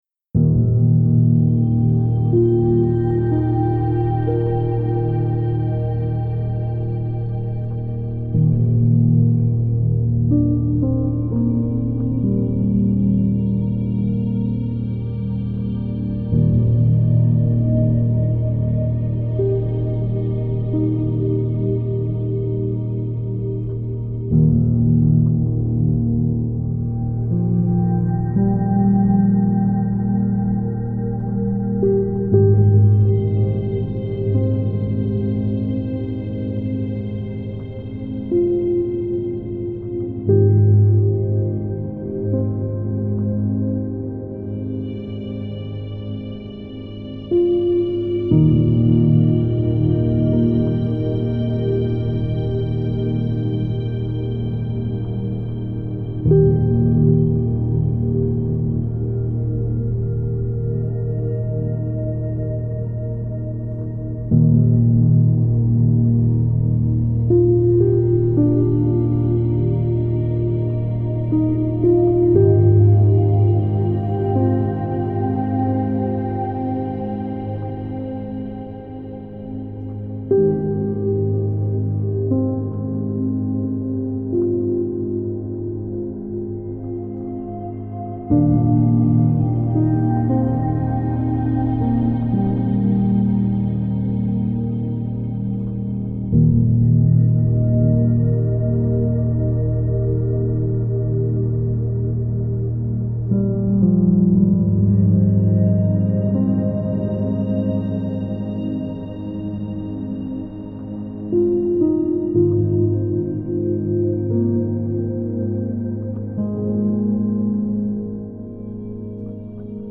это эмоциональная композиция в жанре неоклассической музыки